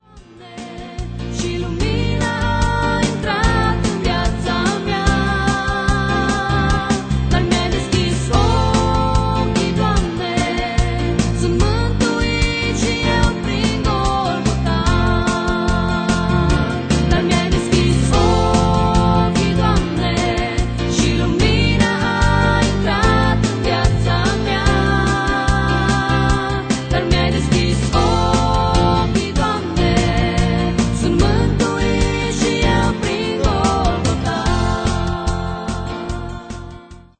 si vocile de exceptie.